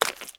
STEPS Swamp, Walk 15.wav